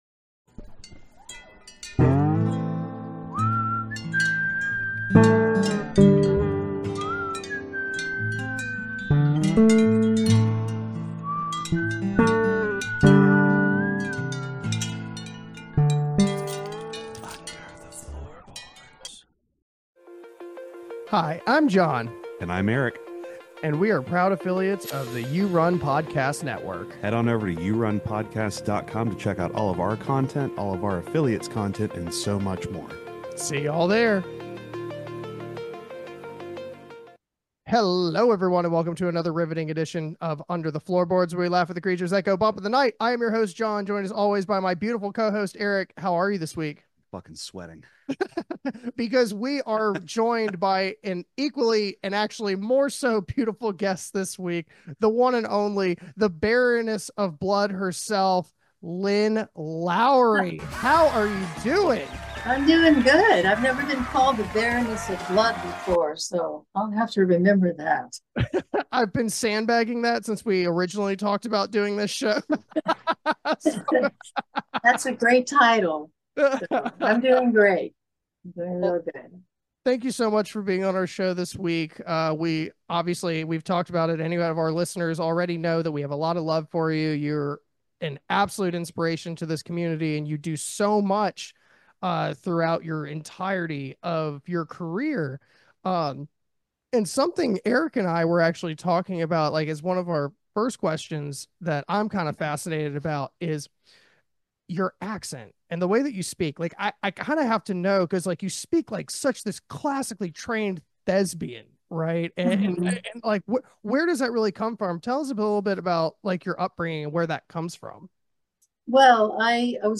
Lynn Lowry- Interview w/ Horror Legend